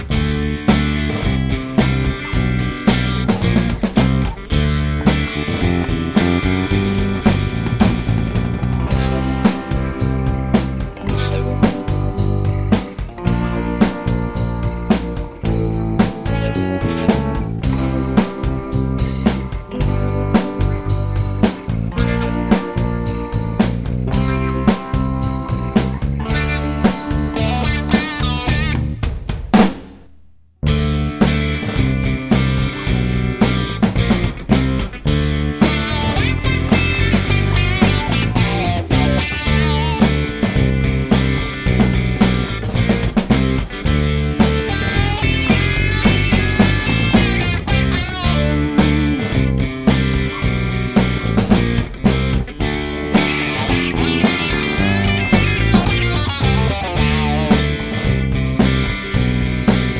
no Backing Vocals Blues 4:07 Buy £1.50